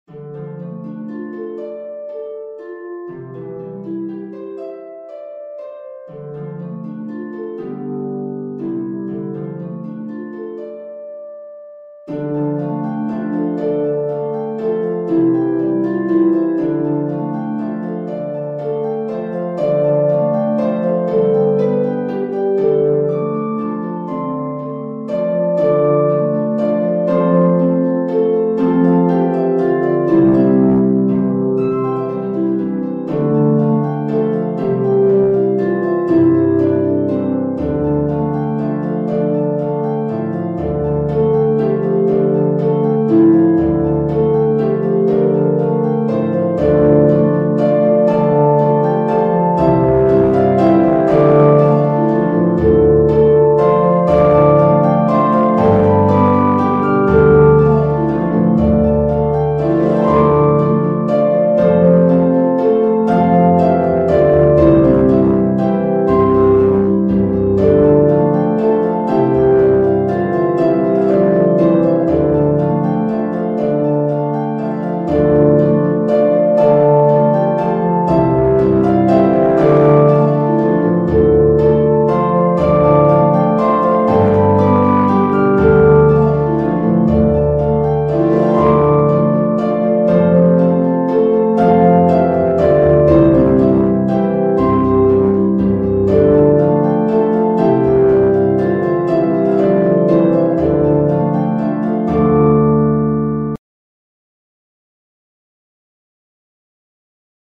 LEVER HARP (34 string minimum) or PEDAL HARP